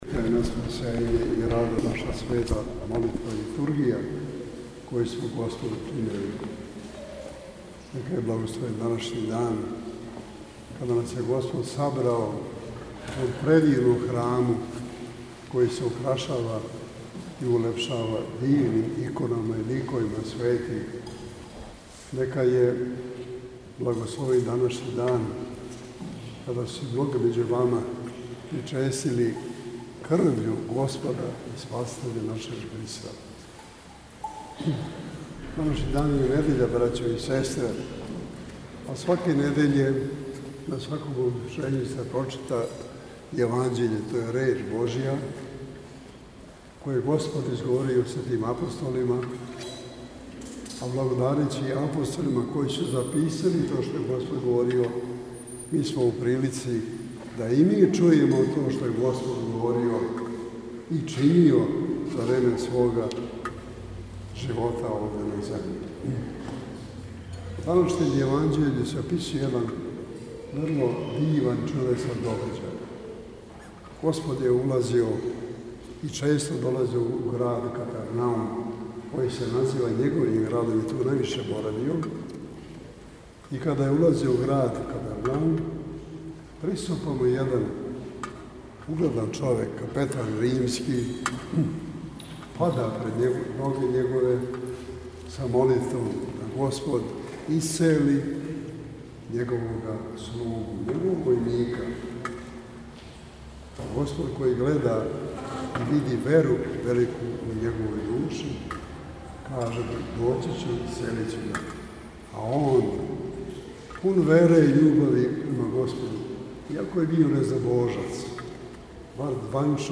Његова Светост Патријарх српски г. Иринеј служио је на празник светих Козме и Дамјана у храму Светог Луке у београдском насељу Кошутњак
На крају Литургије Његова Светост се обратио присутнима беседом у којој је истако значај вере.